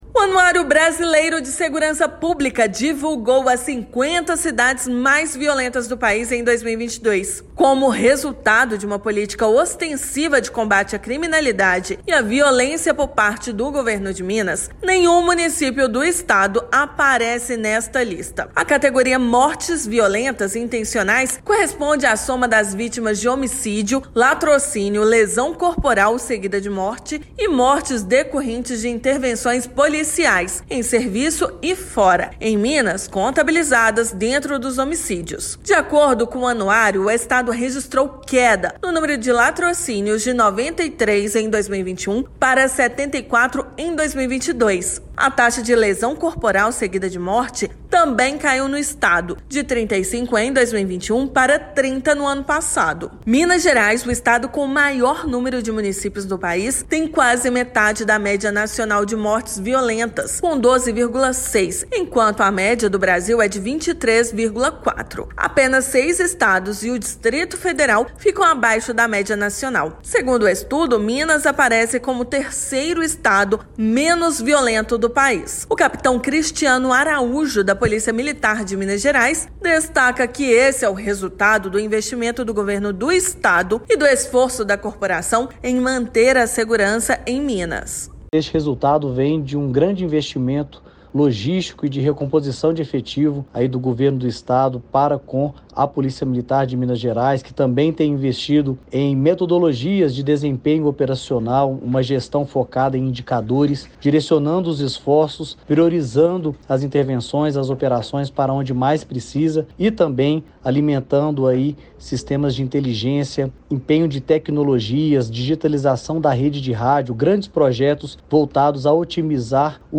[RÁDIO] Segurança: Minas Gerais não tem nenhuma cidade entre as 50 mais violentas do país
Como resultado de uma política ostensiva de combate à criminalidade e à violência por parte do Governo de Minas, nenhum município do estado aparece nessa lista. Ouça matéria de rádio.